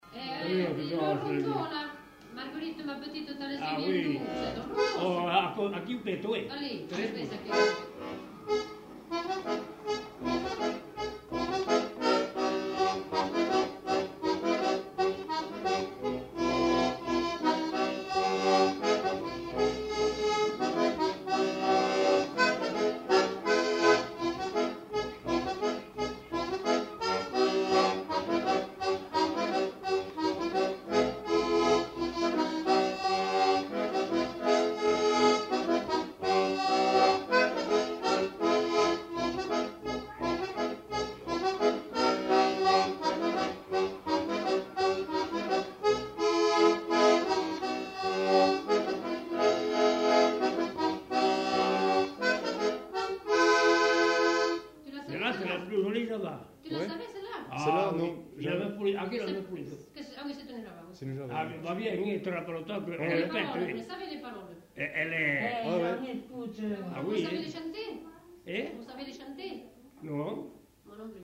Genre : morceau instrumental
Instrument de musique : accordéon diatonique
Danse : varsovienne